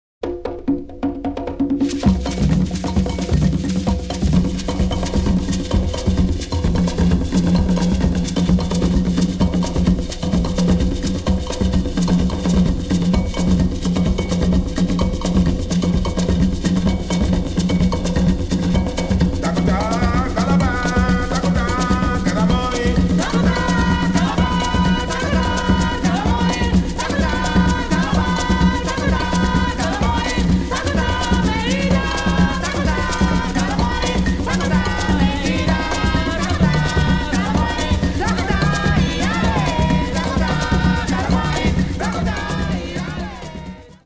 FREE SOUL/RARE GROOVE